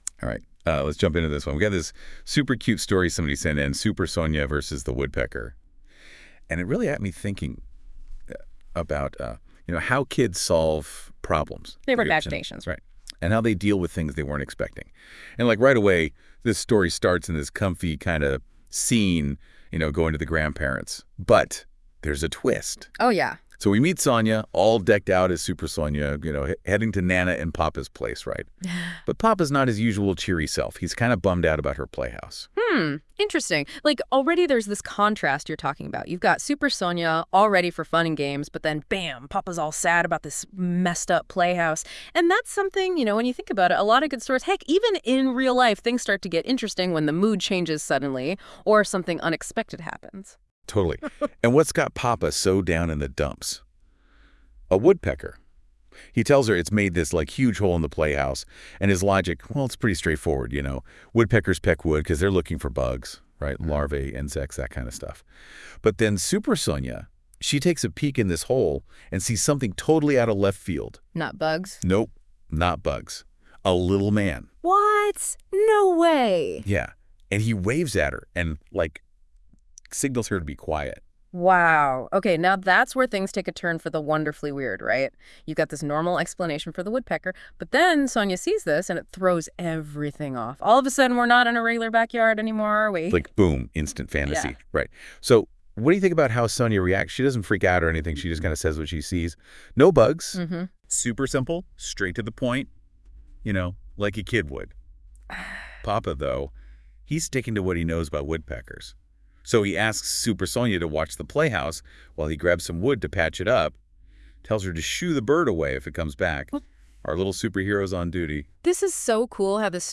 A conversation about – Super Sonya vs. the Woodpecker